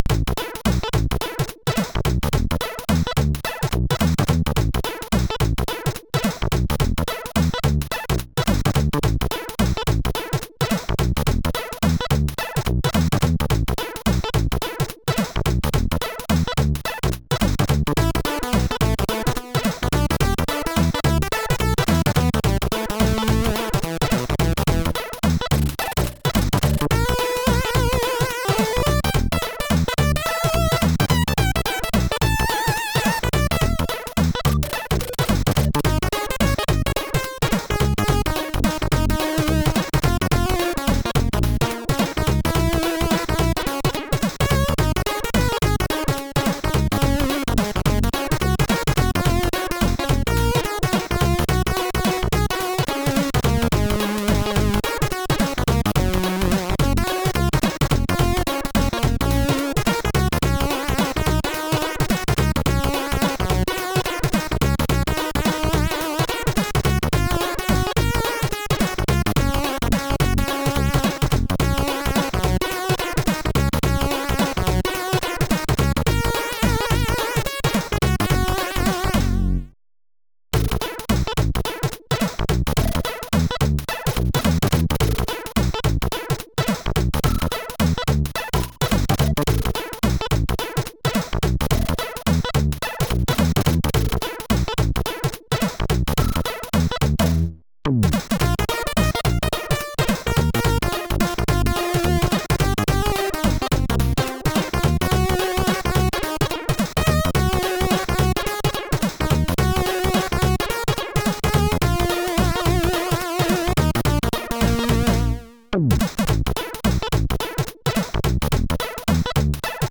Commodore SID Music File
Funky 1.mp3